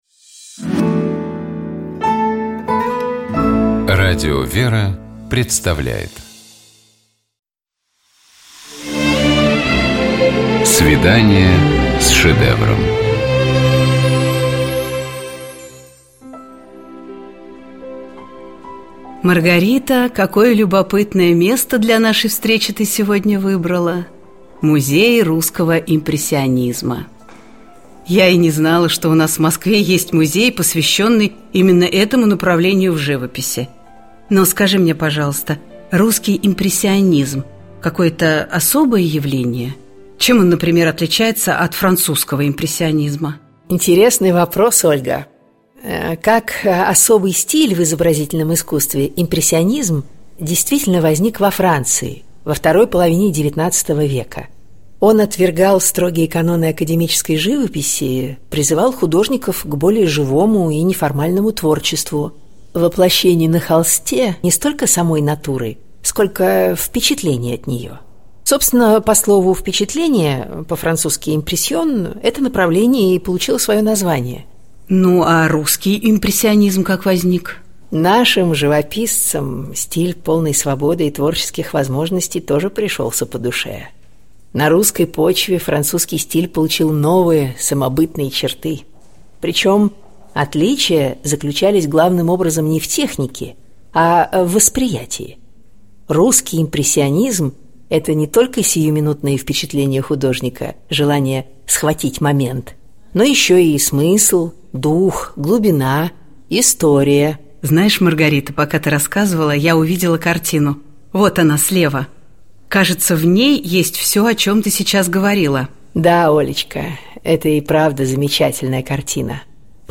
Коридор картиной галереи с посетителями.